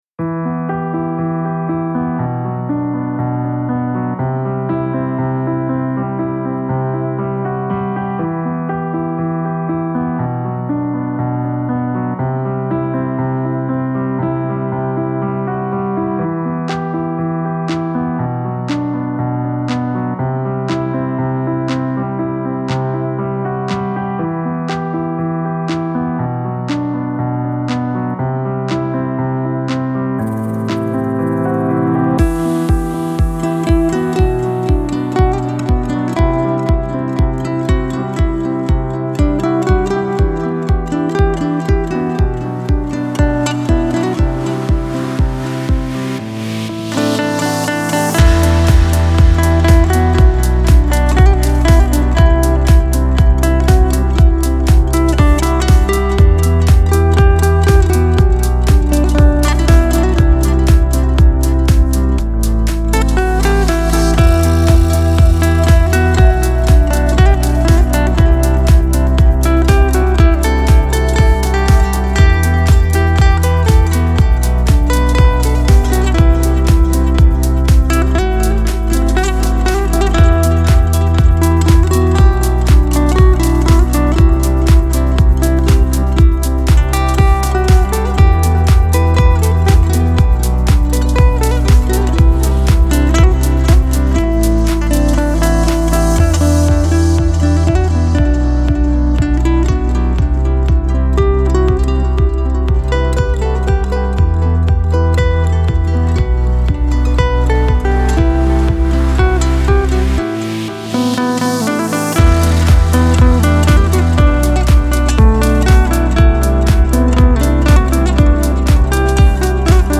دیپ هاوس , ریتمیک آرام , موسیقی بی کلام